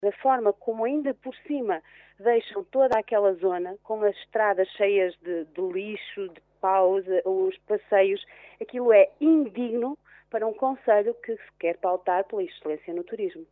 Os vereadores do PSD manifestaram ontem na Reunião de Câmara o seu total desagrado pelo desbaste de inúmeras árvores, nomeadamente “pinheiros e eucaliptos centenários”, que dizem estar a ser feito na Mata Nacional do Camarido, pelo Instituto de Conservação das Florestas e Natureza (ICNF).